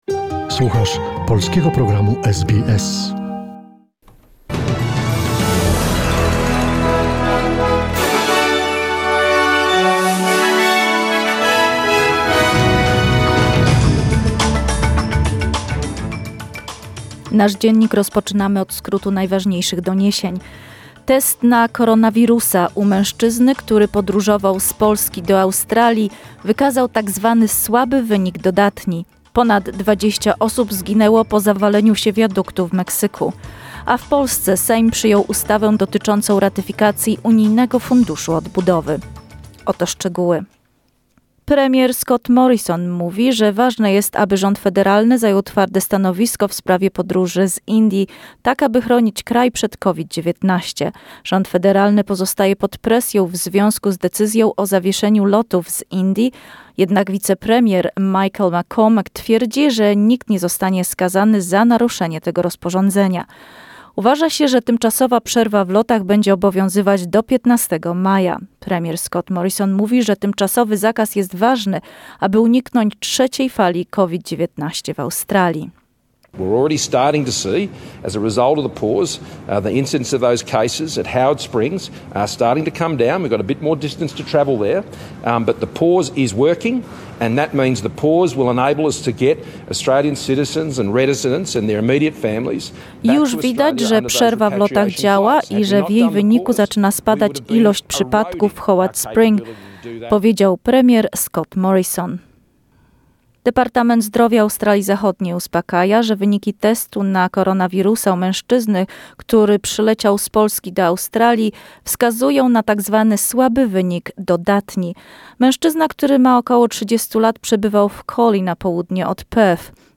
Wiadomości SBS, 5 maja 2021 r.